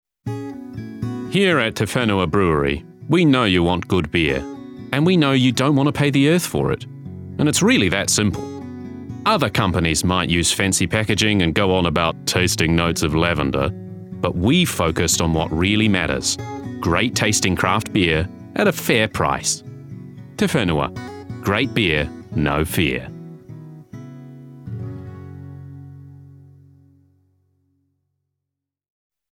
Commercial: Authentic